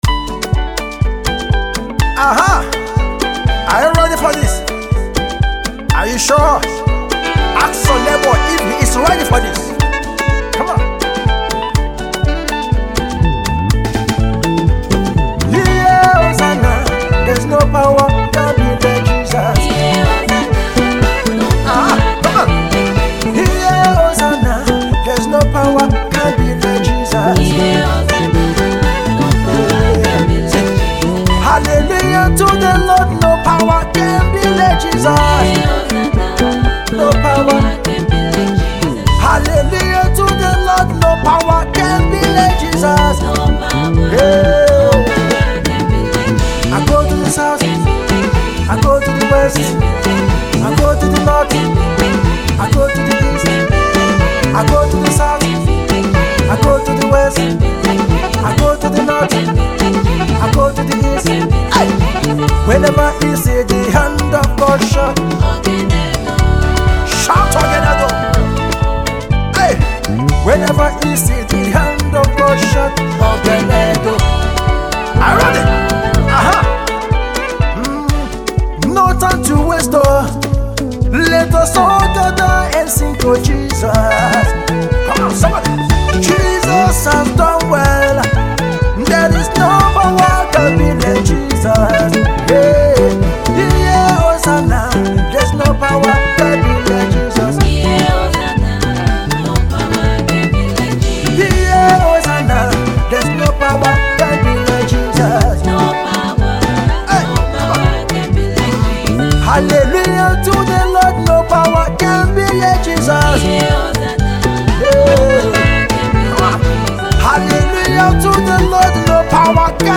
and the result is a soukous African praise tune.